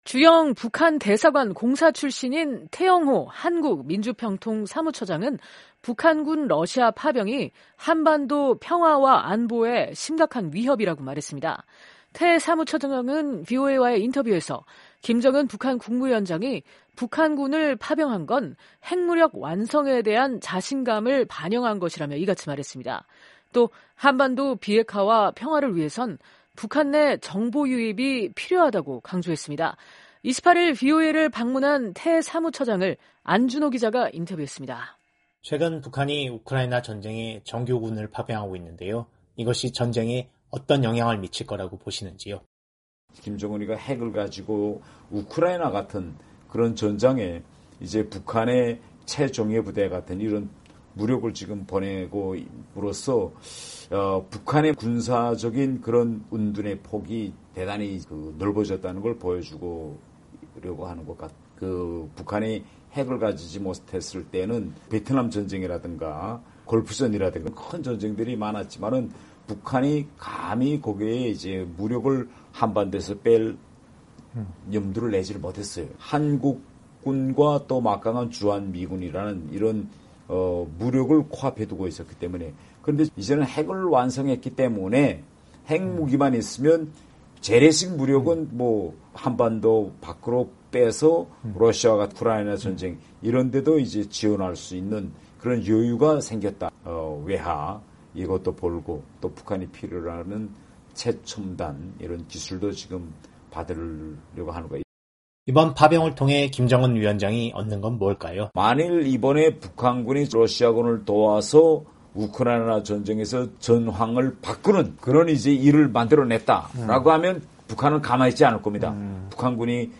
[인터뷰: 태영호 사무처장] “김정은, 러 파병으로 자신감∙∙∙심각한 위협”
태 사무처장은 VOA와의 인터뷰에서 김정은 북한 국무위원장이 북한군을 파병한 것은 핵무력 완성에 대한 자신감을 반영한 것이라며 이같이 말했습니다. 또 한반도 비핵화와 평화를 위해서는 북한 내 정보 유입이 필요하다고 강조했습니다.